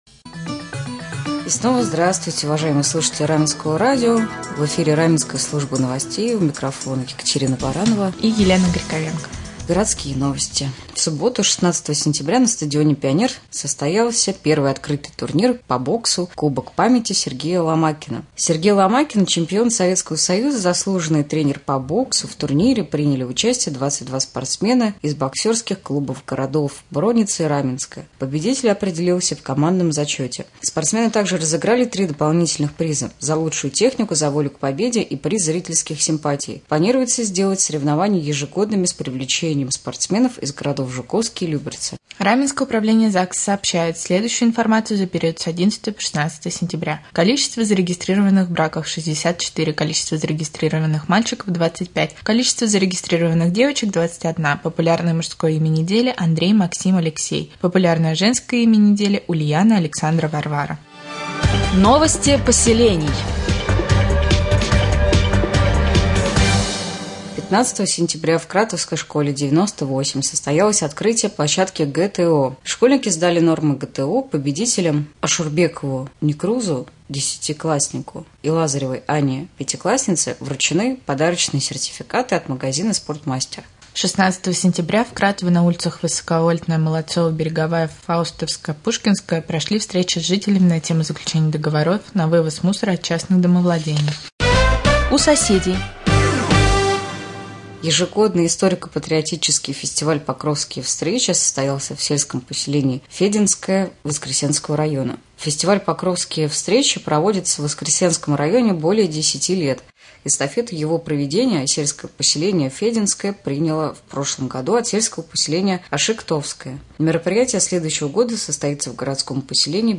1. Новости
5. Гороскоп